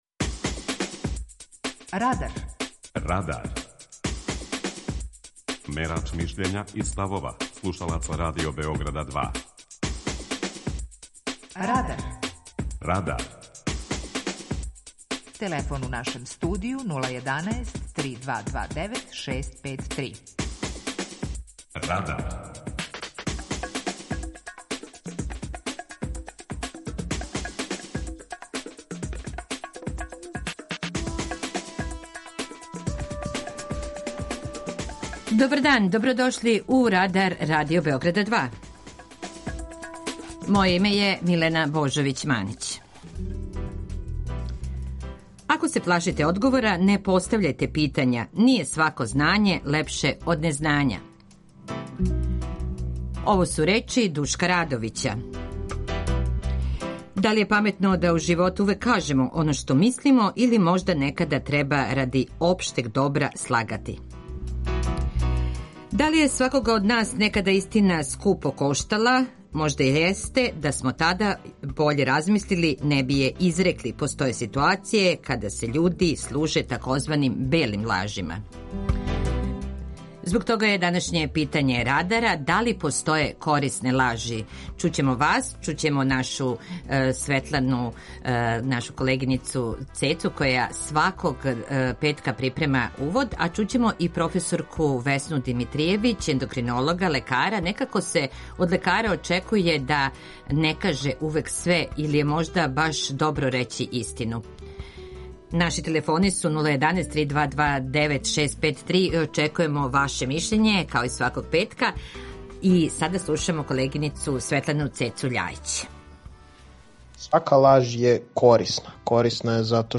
Питање Радара гласи: Да ли постоје корисне лажи? преузми : 18.96 MB Радар Autor: Група аутора У емисији „Радар", гости и слушаоци разговарају о актуелним темама из друштвеног и културног живота.